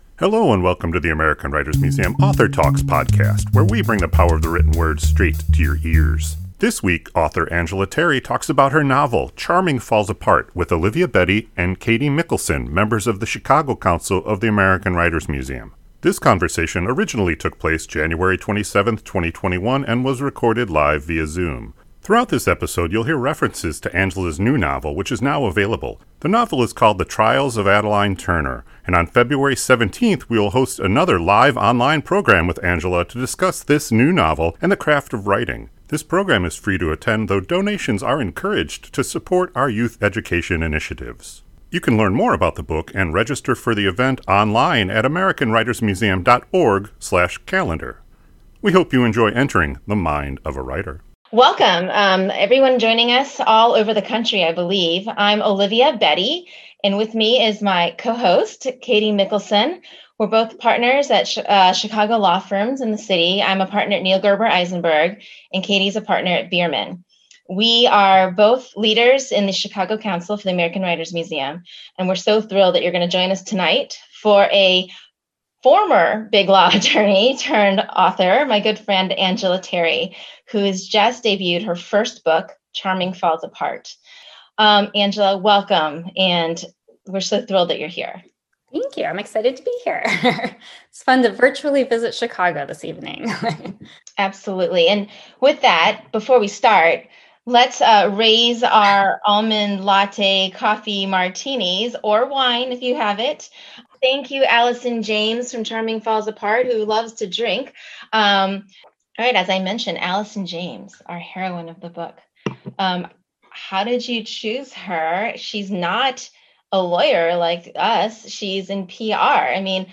This conversation originally took place January 27th, 2021 and was recorded live via Zoom.